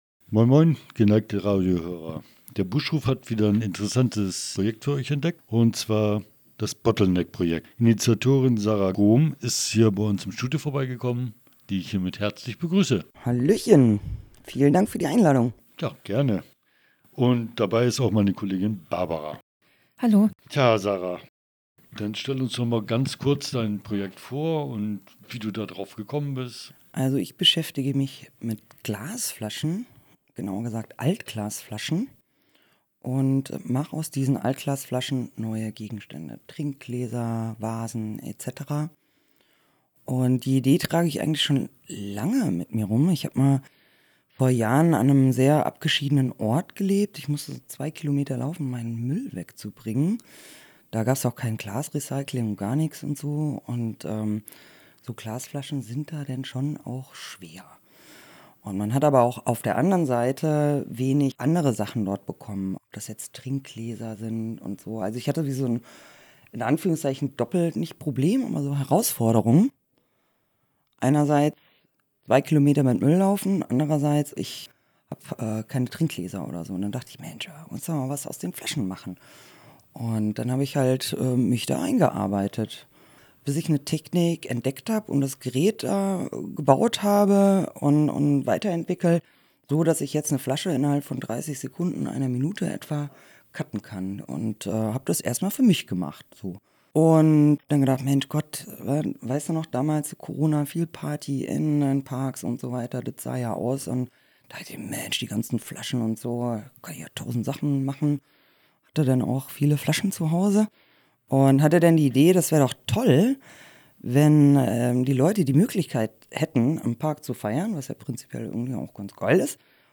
In einem spannenden Interview